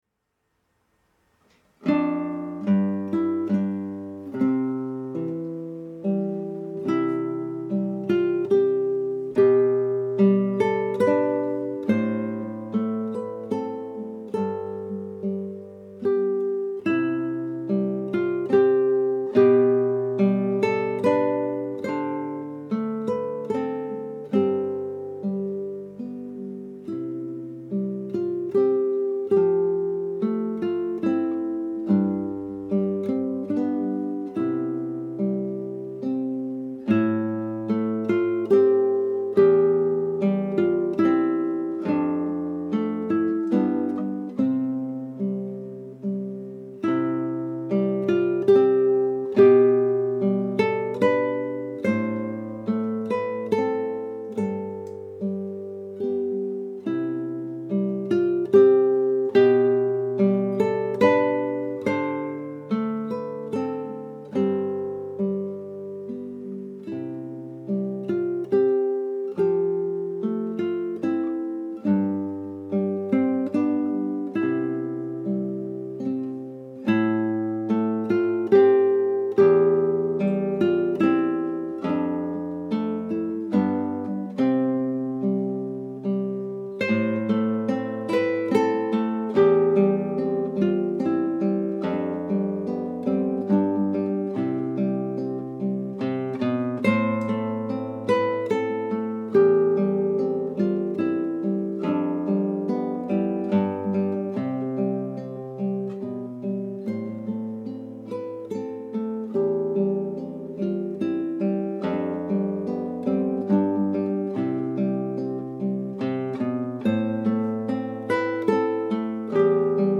Unaccompanied version at a gentle speed
Some of these mp3s are in mono.